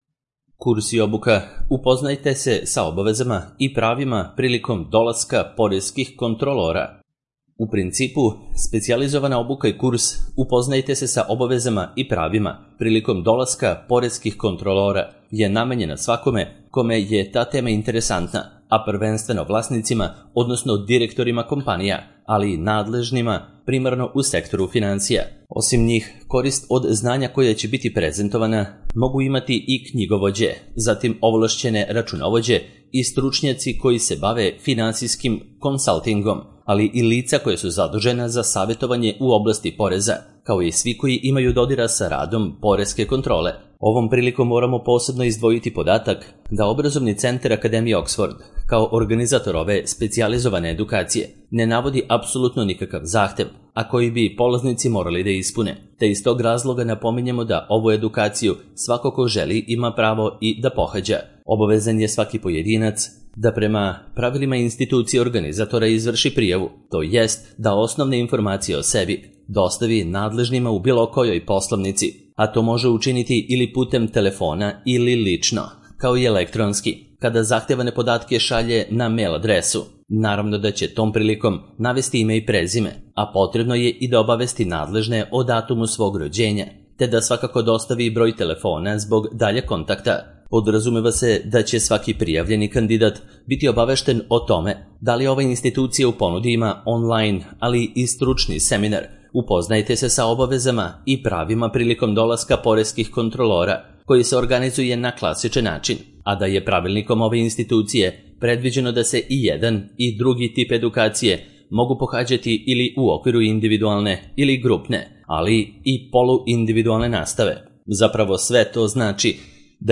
Audio verzija teksta